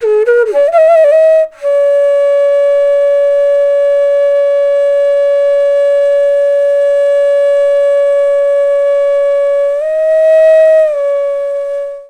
FLUTE-A08 -R.wav